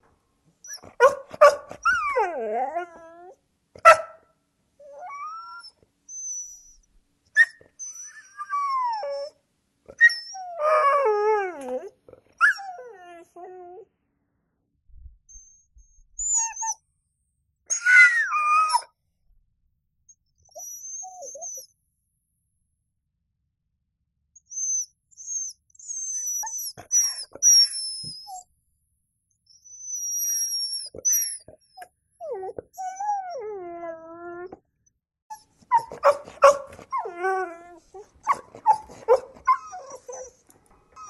クーンギューンキュンキュン・・。
犬が悲しい時の鳴き声 着信音
犬が不安で不安でたまらない気持ちを鳴き声であらわしている様子。